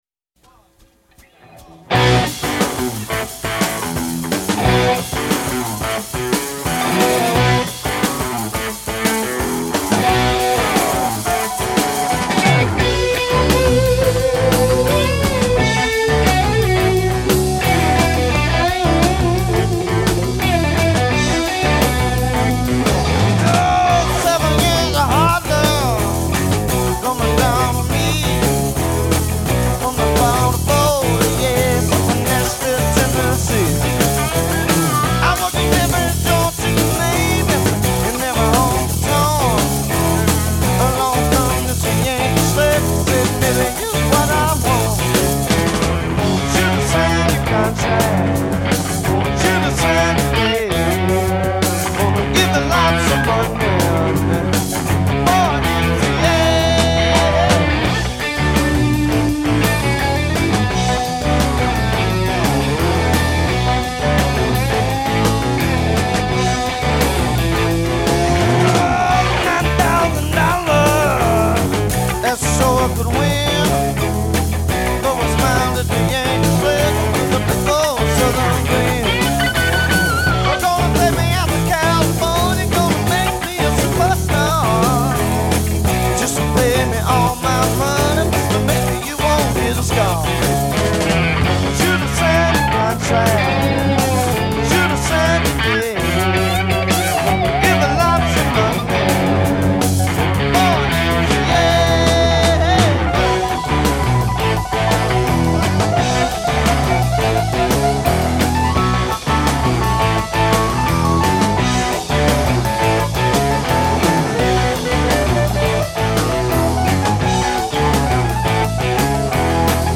Rare live-in-the-studio performance